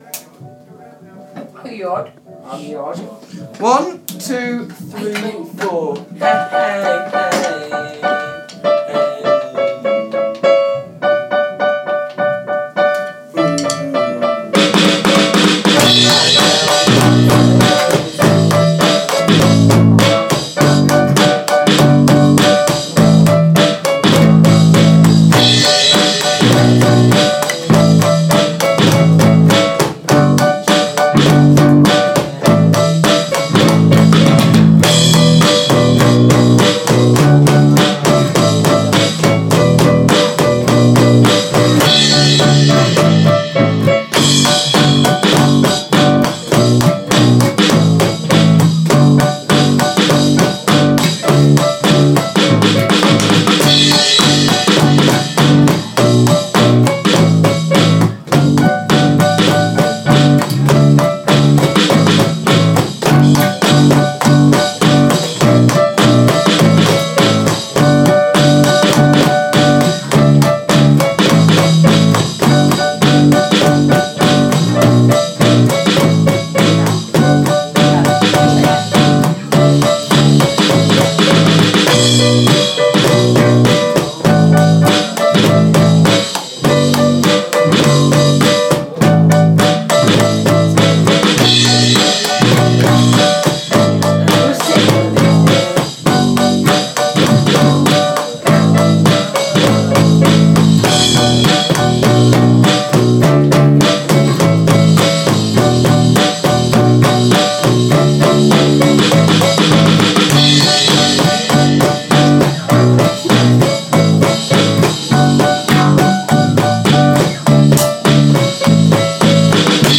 full recording band